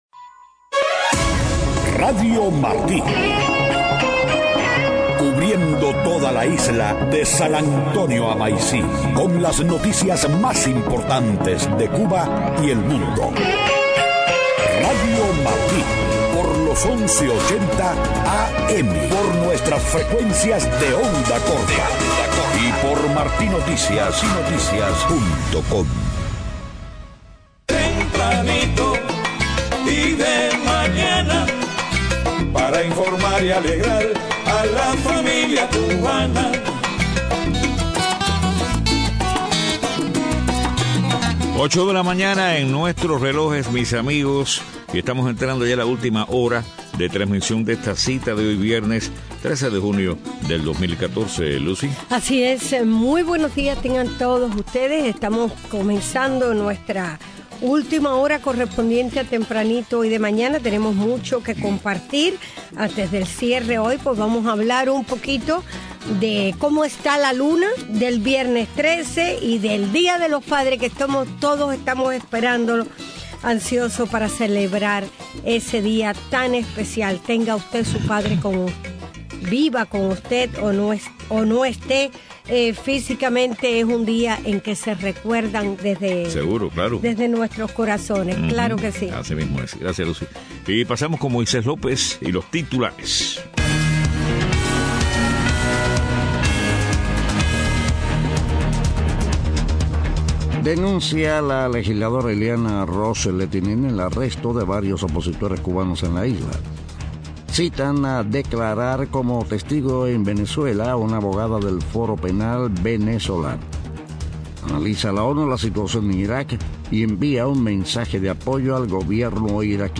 Estado del tiempo. Deportes.